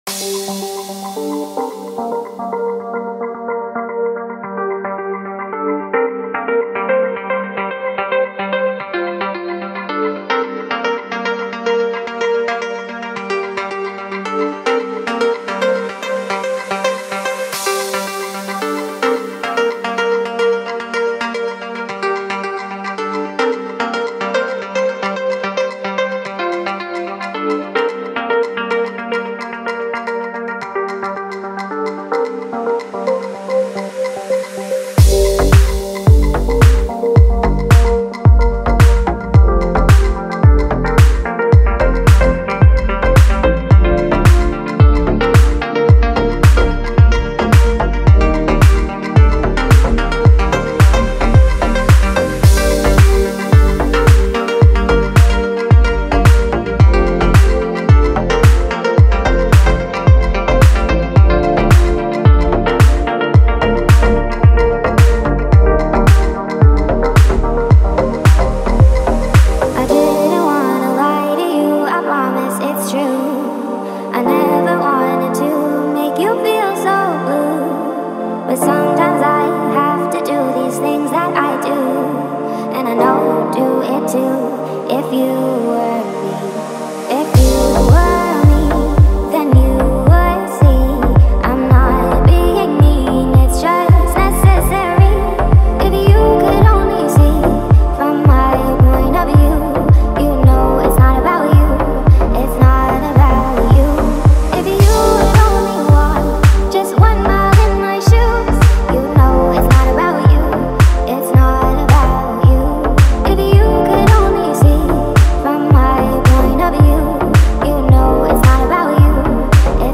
это меланхоличная и атмосферная композиция в жанре инди-поп.